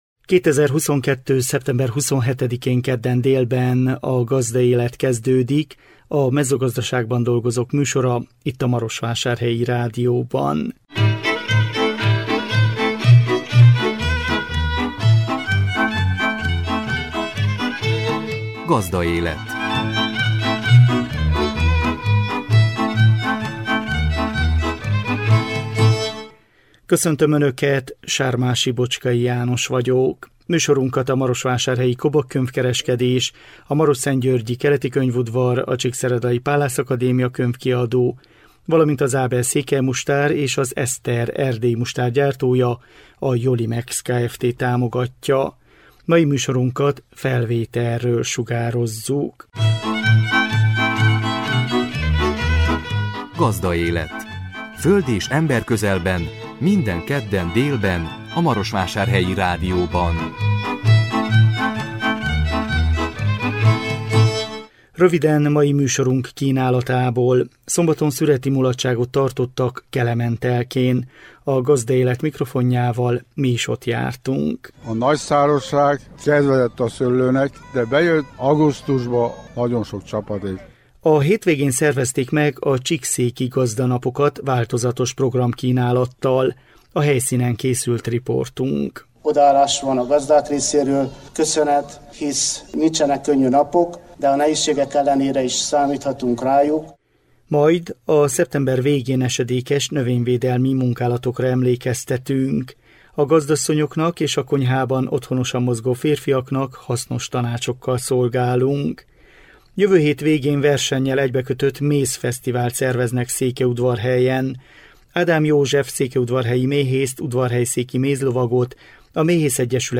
A 2022 szeptember 27-én jelentkező műsor tartalma: Szombaton szüreti mulatságot tartottak Kelementelkén. A Gazdaélet mikrofonjával mi is ott jártunk.
A helyszínen készült riportunk. Majd a szeptember végén esedékes növényvédelmi munkálatokra emlékeztetünk.